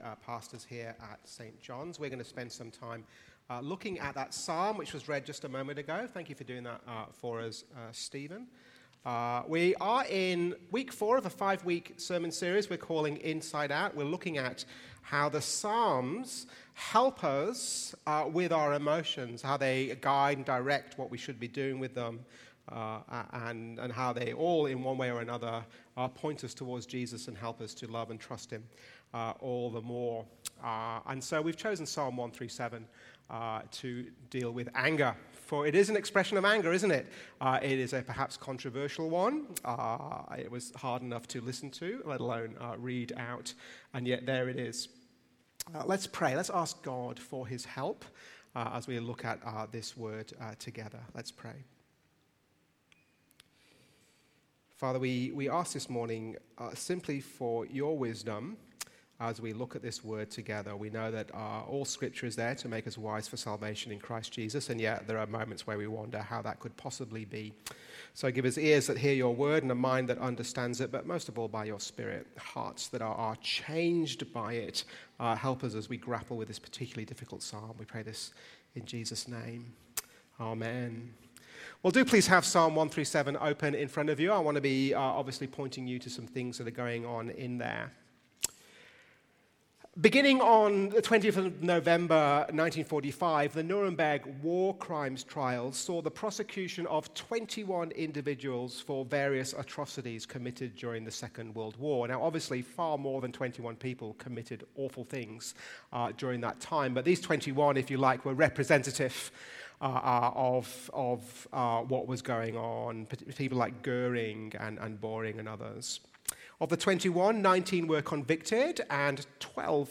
Sermons | St Johns Anglican Cathedral Parramatta
Watch the full service on YouTube or listen to the sermon audio only.